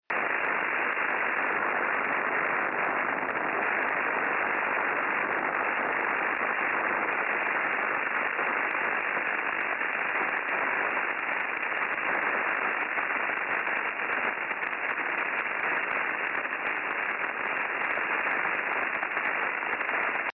A metà novembre è comparso un grosso disturbo che mi creava grossi problemi di ricezione in onde corte Il disturbo era' presente in maniera costante da 500 Khz a 22 Mhz, e si presentava come una serie di segnali modulati in frequenza distanti tra di loro circa 70 Khz.
registrazione di uno dei segnali effettuata con il mio IC 756 sintonizzato sui 20486 Khz (20486.mp3).